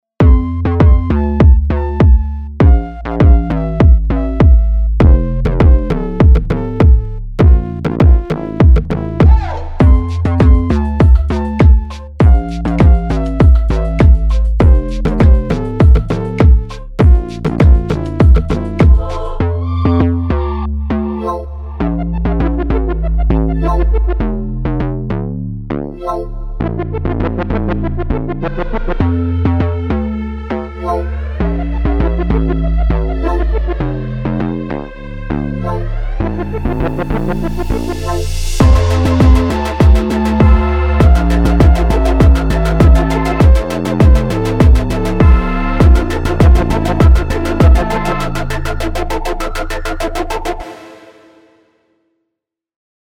UNEASY ELECTRO
Dark / Energy / Tension / Bounce